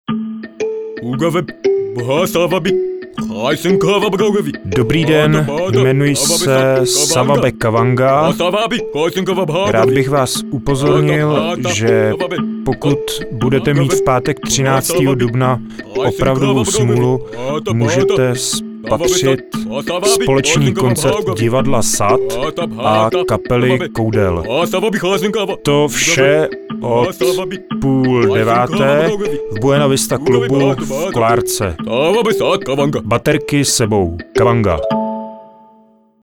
Původní zvuková pozvánka SAD & Koudel gangu